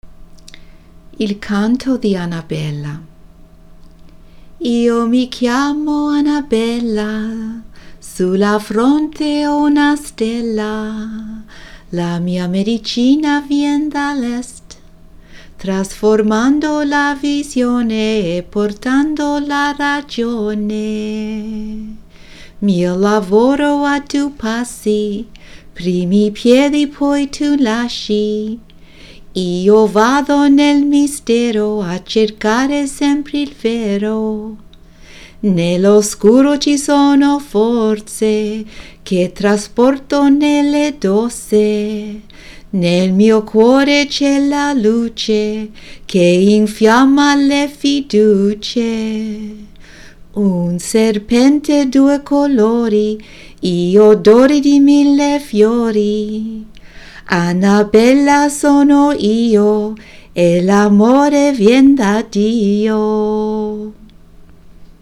Chants Her Truth